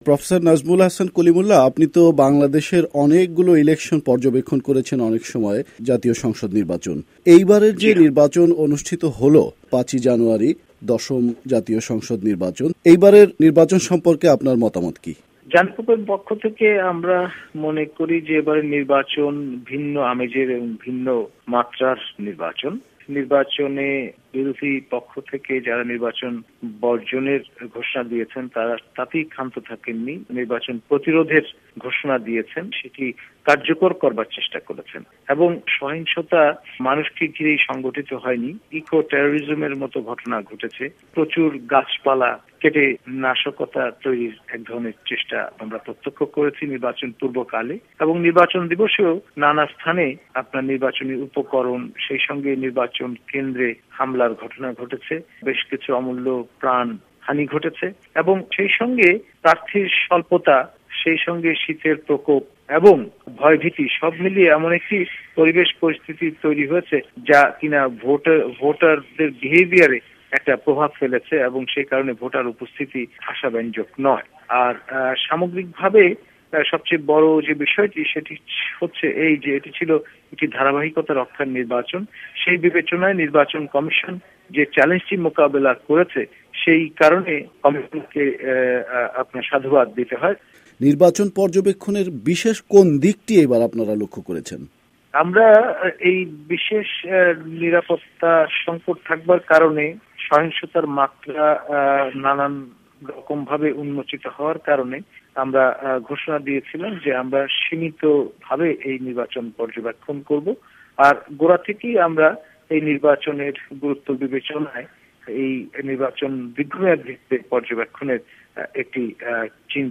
বাংলাদেশে দশম জাতীয় সংসদ নির্বাচনের ভোট হলো পাঁচ জানুয়ারী - VOA সংবাদদাতাদের রিপোর্ট